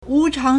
无偿 (無償) wúcháng
wu2chang2.mp3